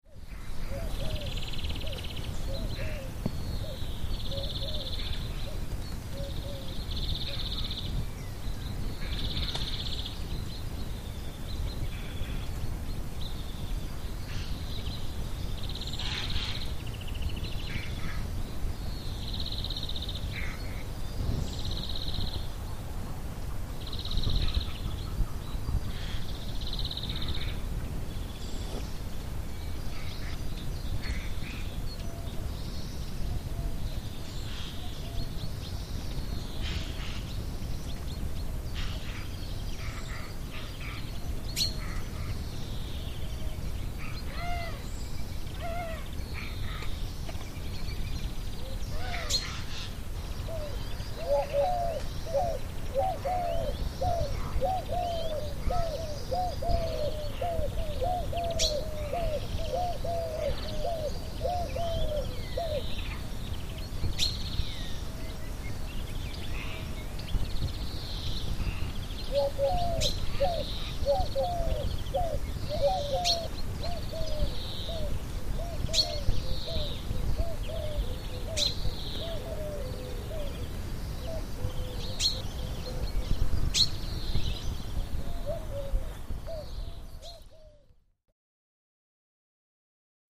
Israel Biblical Reserve, Early Morning Doves, Bird Ambience Near Pond